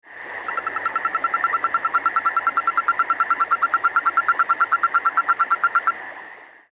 Advertisement Calls
The advertisement call of the Yosemite Toad is a long, loud, rapid musical trill, repeated at frequent intervals.
It seems to vary in pitch and speed among toads, and this might be due to temperature.
Sound  This is a 7 second recording of a male toad calling in the afternoon from a snow-melt pool in a high-altitude wet meadow surrounded by snow at 9200 ft. elevation in Fresno County (shown to the right.)
Pacific Treefrogs and water sounds are heard in the background.